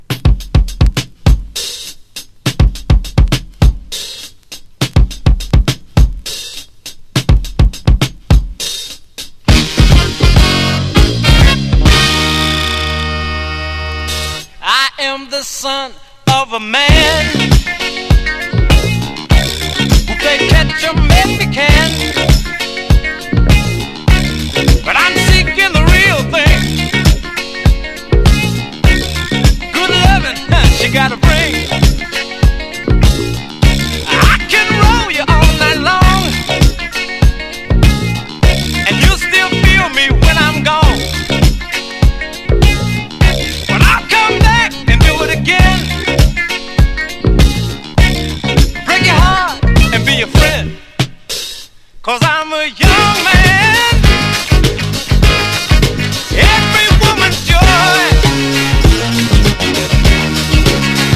ROCK / S.S.W./A.O.R. / COUNTRY ROCK / 80'S / SURF
、ブライトで誰しものノスタルジアを擽る素晴らしい作品に仕上がっています。